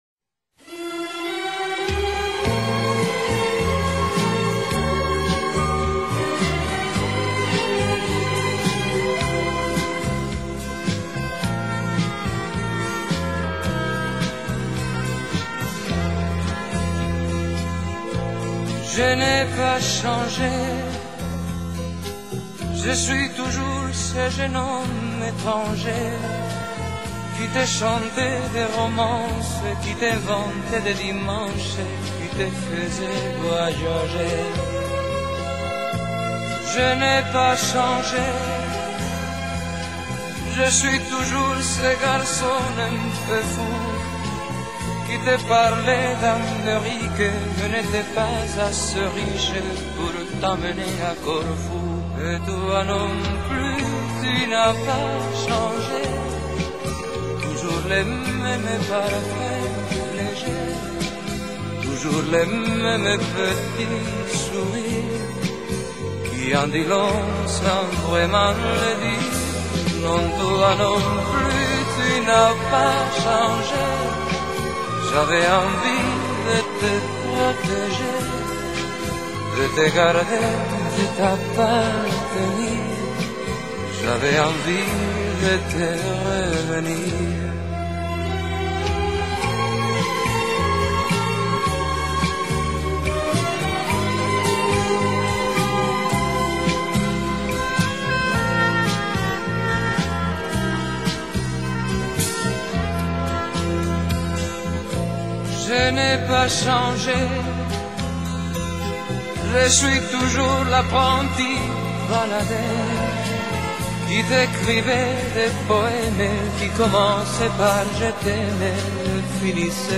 صدای گرم و دل‌نشین